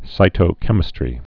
(sītō-kĕmĭ-strē)